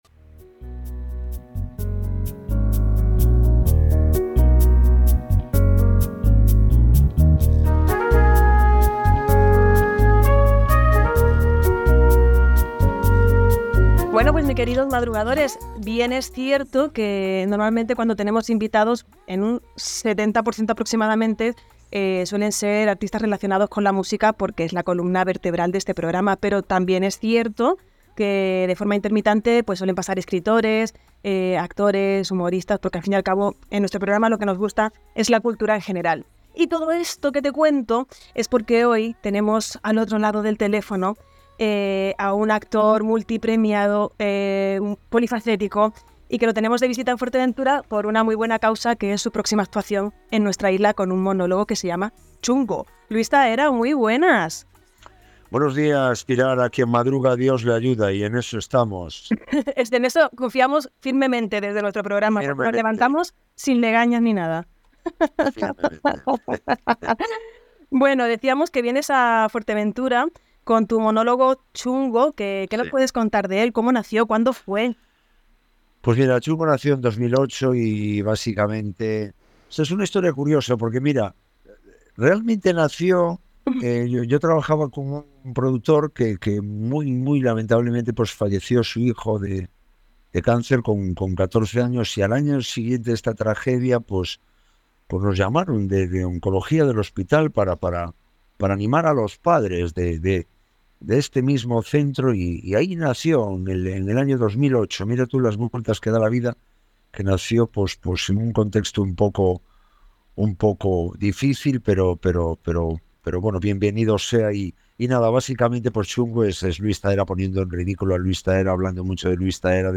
Entrevista-en-el-programa-Suena-Bien-al-actor-Luis-Zahera.mp3